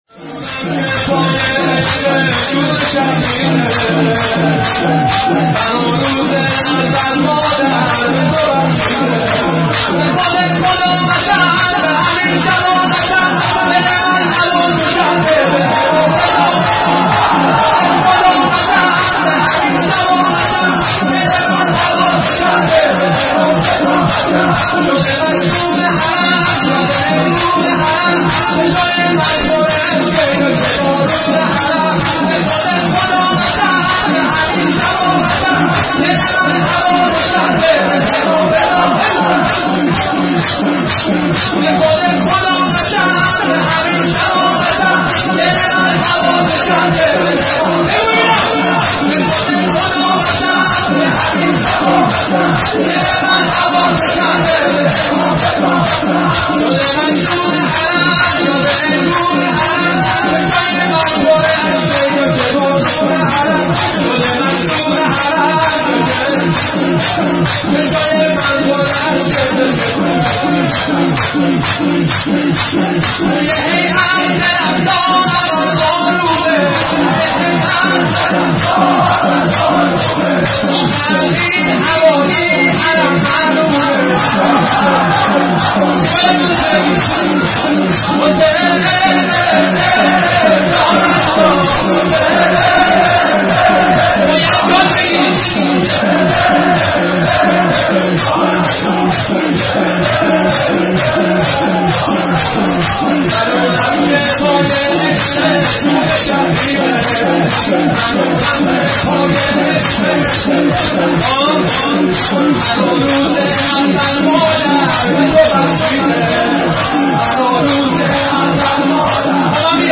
• یکی از قوی ترین مداحی های شور تو زمان خودش